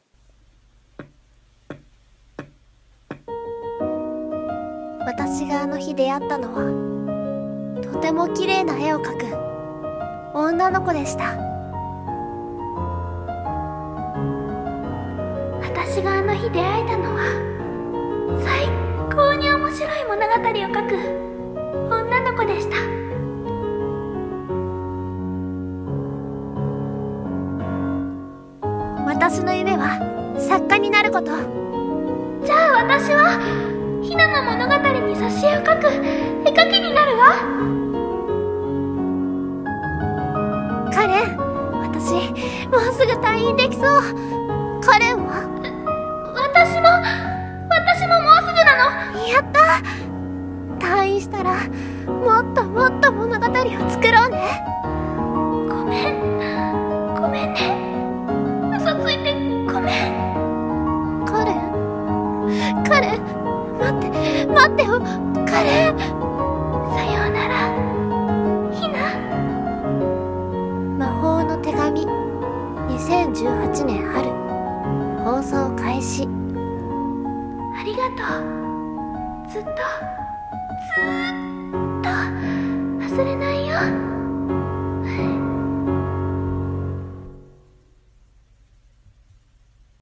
【アニメ告知CM風声劇台本】魔法の手紙